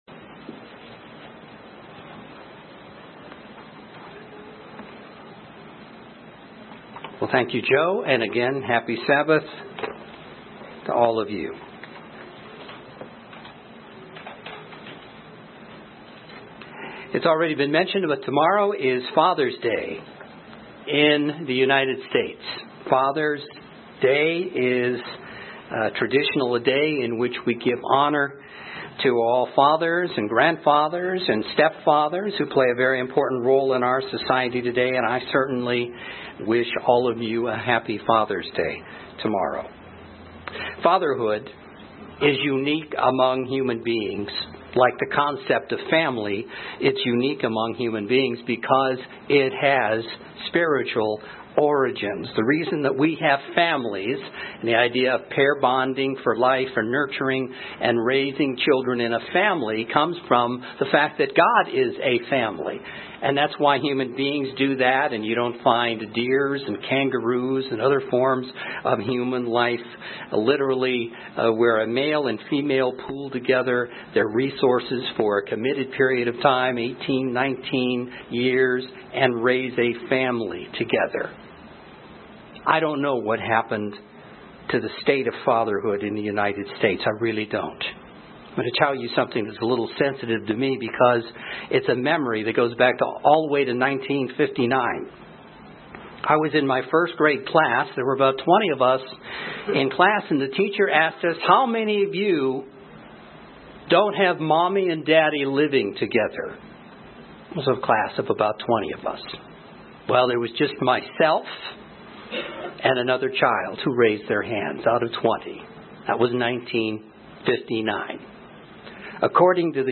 UCG Sermon Transcript This transcript was generated by AI and may contain errors.